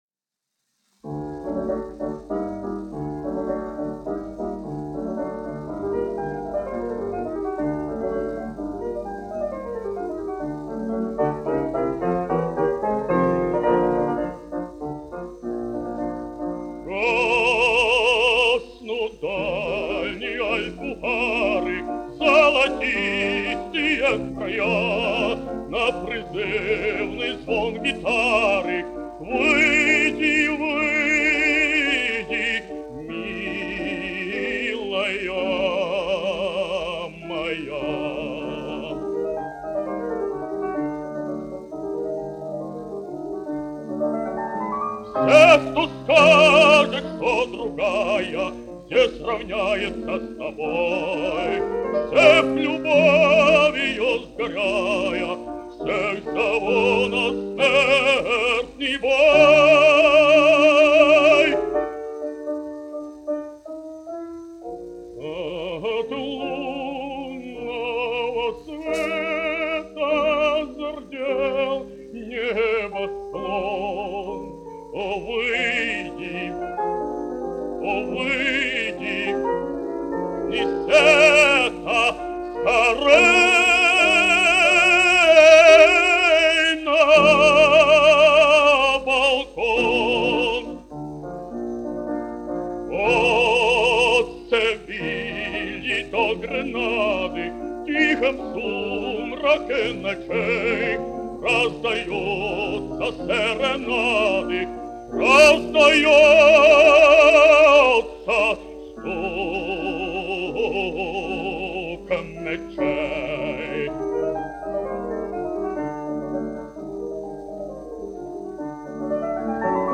1 skpl. : analogs, 78 apgr/min, mono ; 25 cm
Dziesmas (vidēja balss) ar klavierēm
Skatuves mūzika--Fragmenti
Latvijas vēsturiskie šellaka skaņuplašu ieraksti (Kolekcija)